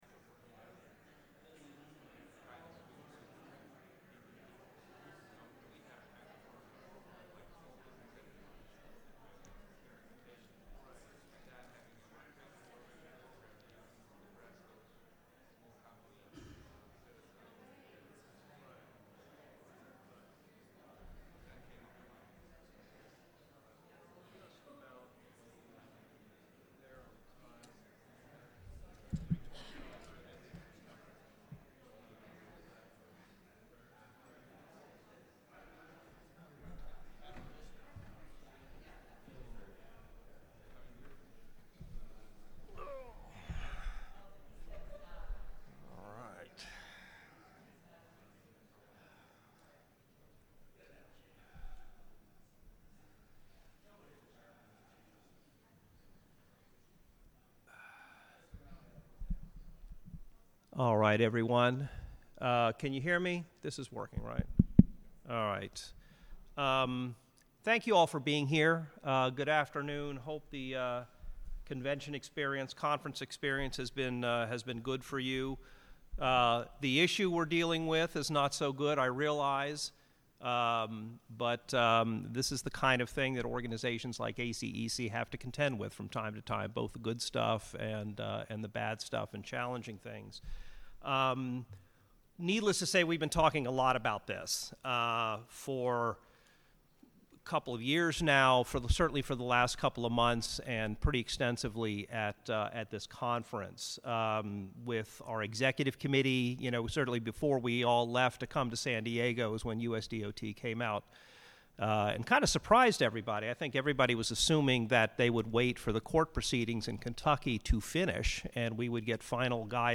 DBE_Session_Audio_Fall_Conference-2.mp3